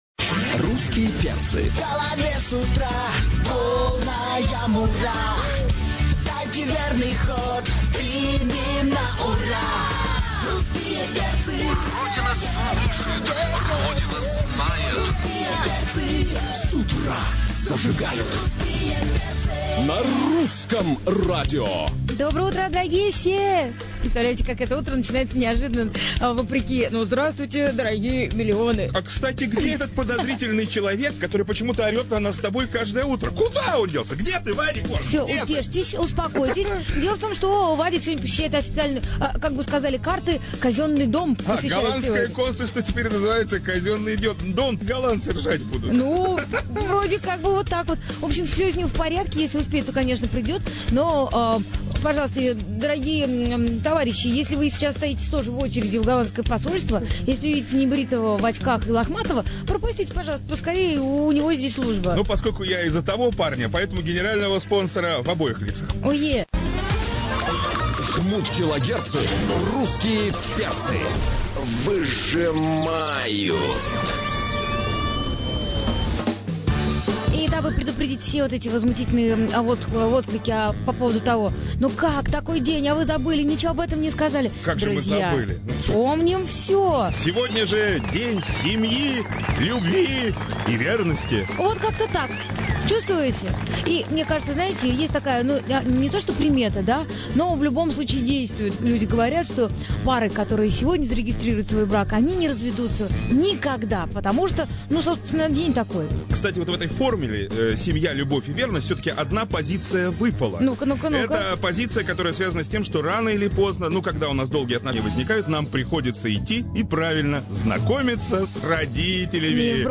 Шоу "Русские перцы" на Русском радио. Запись эфира.
Читайте также: "Русские перцы" ушли с "Русского радио" | Теги : русские перцы , диджей , запись эфира , рубрика , Русское Радио , утреннее шоу , радиоведущий Стриптиз в утреннем шоу "Русские Перцы".